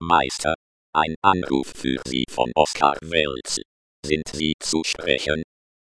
Das Programm nennt sich eSpeakCaller und setzt auf dem freien Text-to-Speech Synthesizer eSpeak auf.
N900-anrufansage.ogg